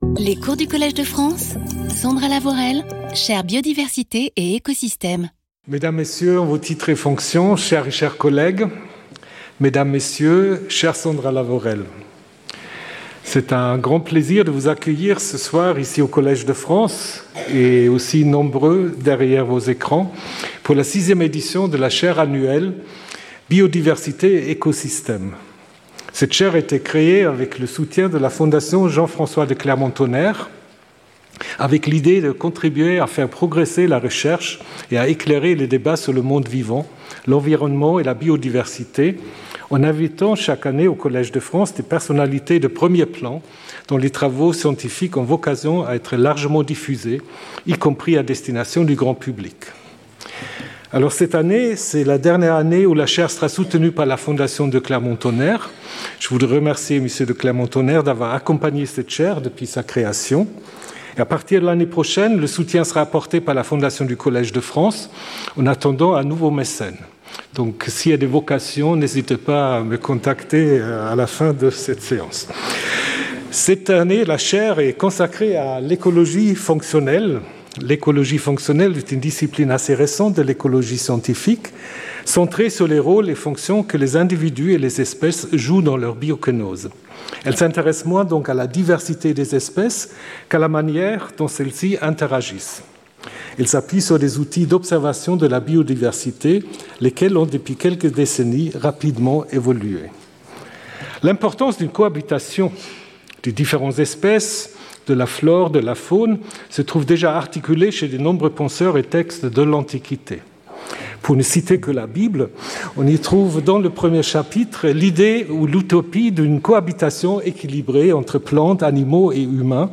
This lecture will introduce the field of functional ecology. After a definition and a brief history, it will present the functional traits that enable a general understanding of the responses of organisms to environmental gradients on the basis of their morphological, physiological, chemical or reproductive traits.